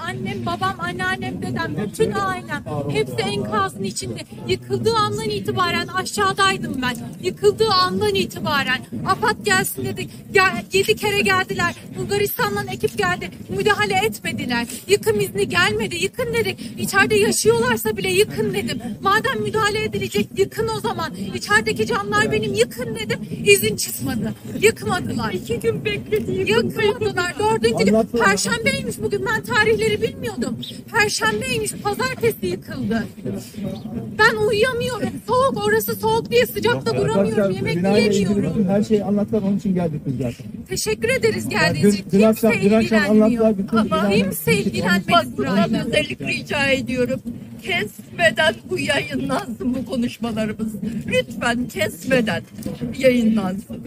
Deprem mağdurlarının sosyal medyada paylaştığı videoları tarayıp ses dosyalarına dönüştürerek internet sitesine yükledik.